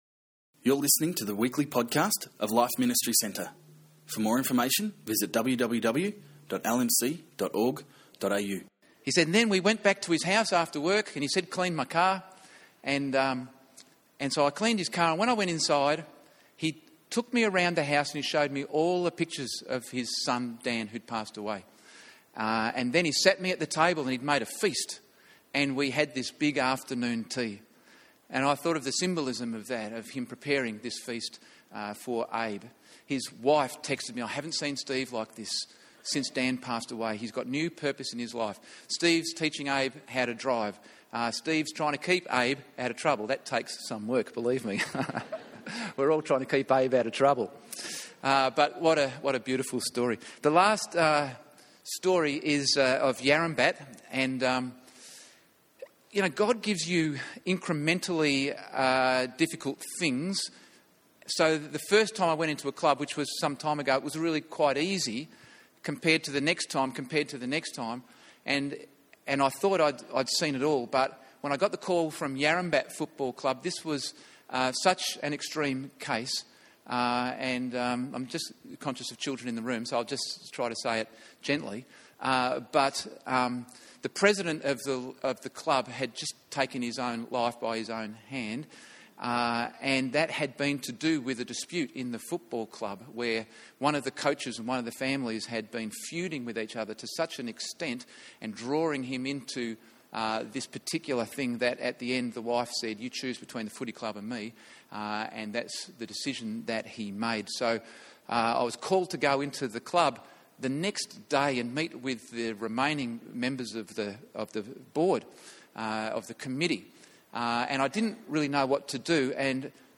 Unfortunately, the whole message is not available. You will be hearing it from halfway.